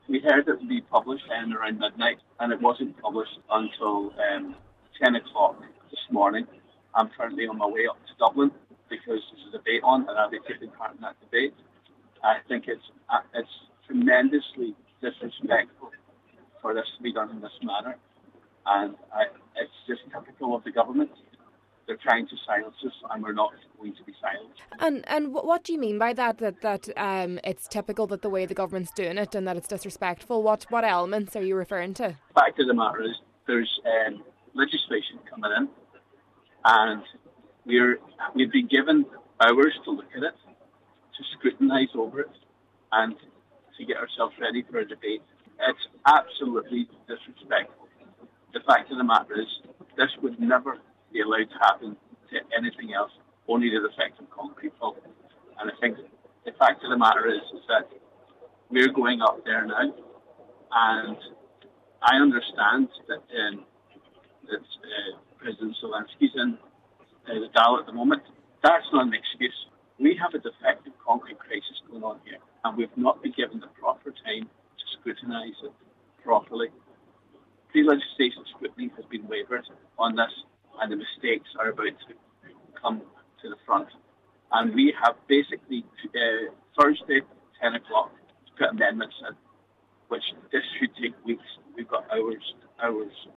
Deputy Ward says more time should have been afforded to Dáil members to properly examine the effects these changes will have for affected homeowners: